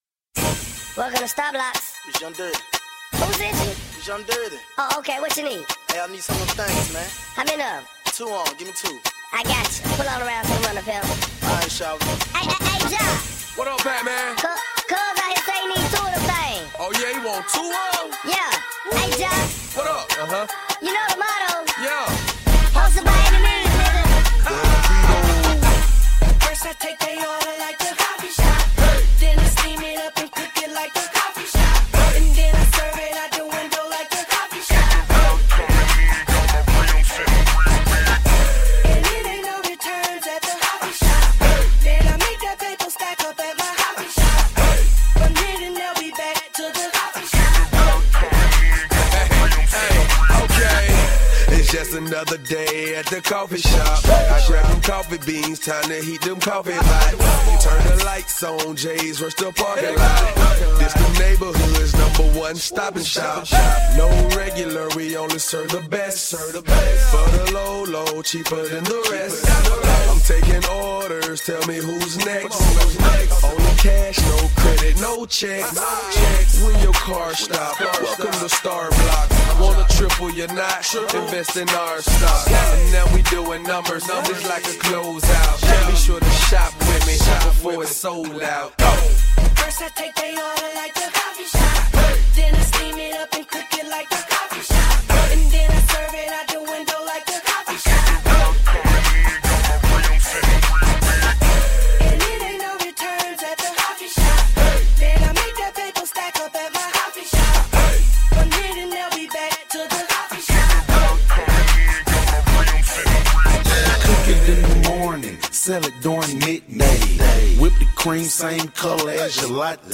Die dinger sind halt echt fordernd und schlagen einfach zu.